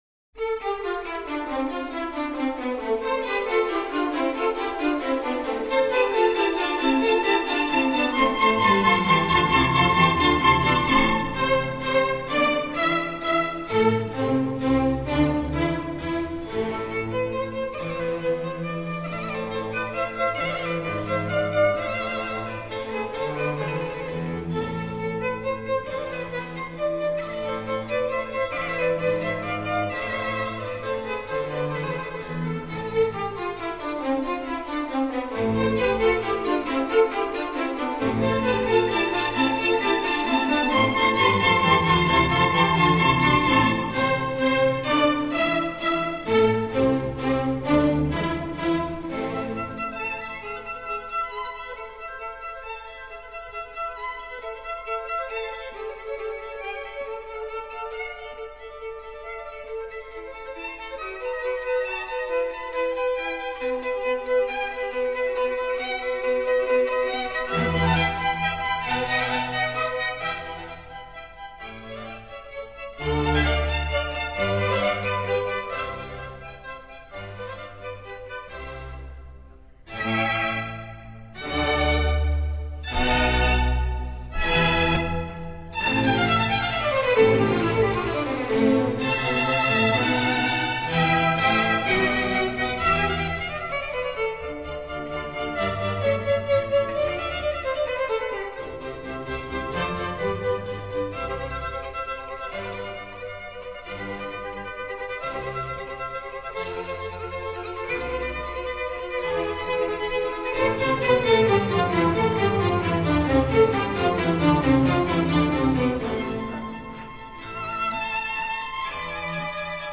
vivaldi-concerto-for-two-violins-in-a-minor-3rd-mov-allegro.mp3